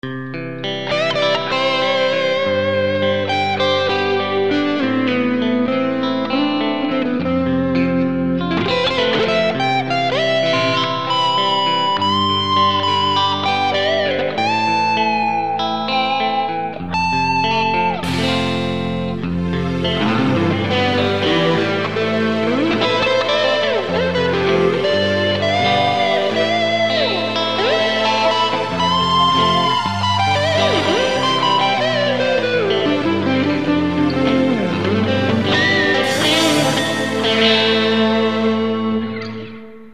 banez RGR620-ROCKTRON VooDu Valve / Roland SC-88VL
浅い歪みでの渋系バラードプレイ。ストリングスとか絡んでくる。